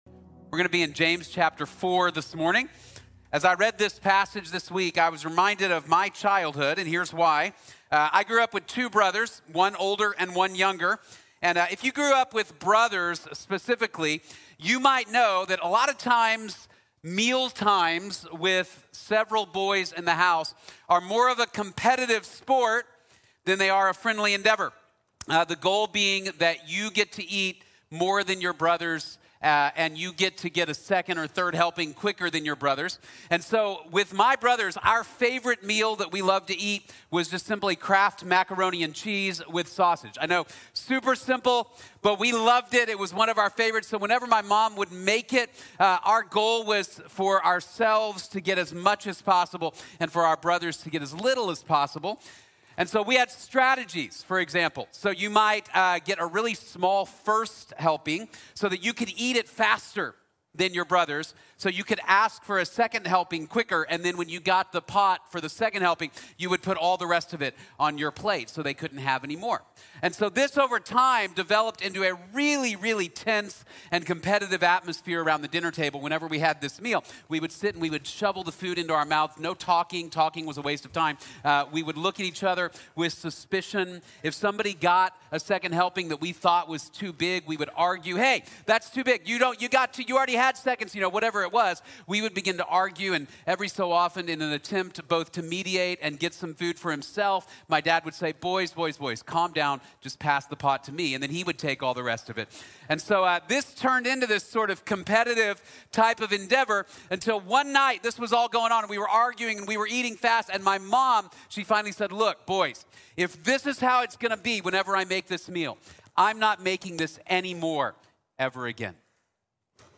El arrepentimiento lleva a la paz | Sermón | Iglesia Bíblica de la Gracia